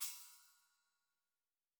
TC PERC 06.wav